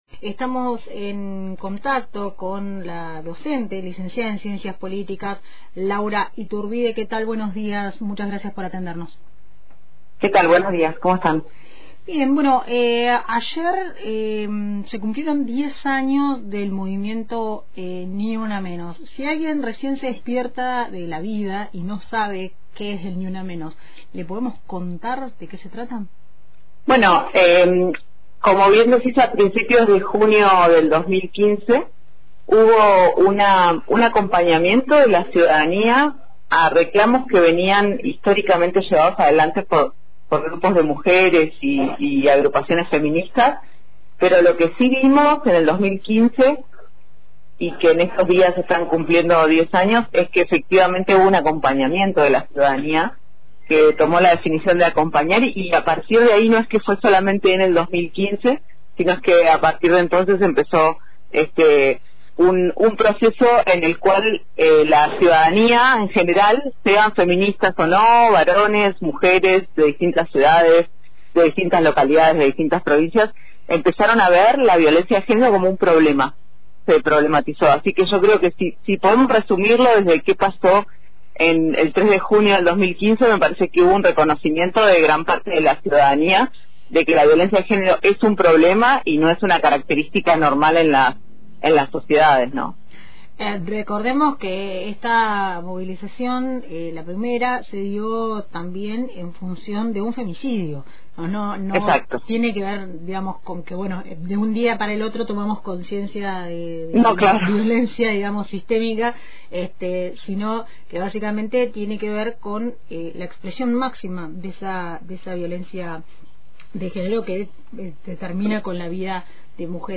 En diálogo con Radio Antena Libre
entrevista